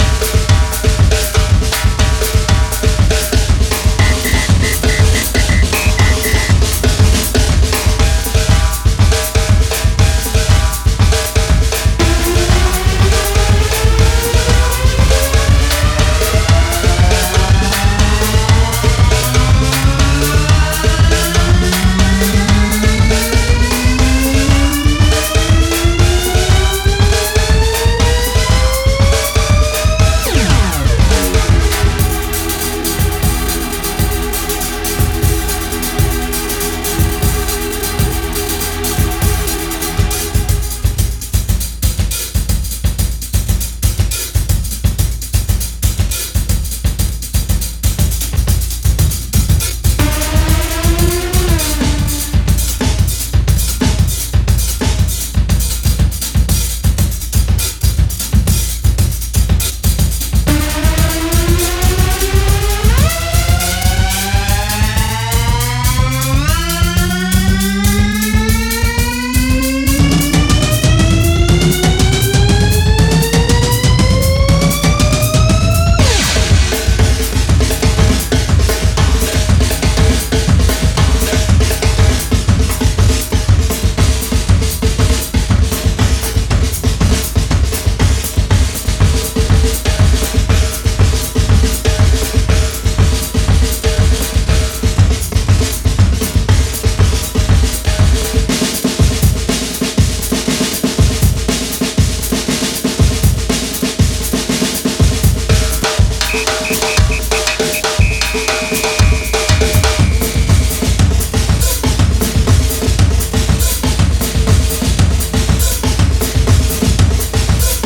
A面2曲では特にギリギリのユーモアが発揮され、フロアに白熱と苦笑の両方をもたらすこと間違い無しです。